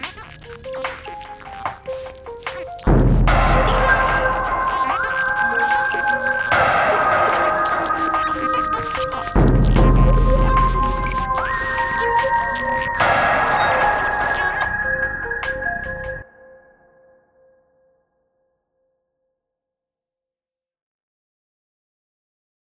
Whereas the remaster has a conclusive ending: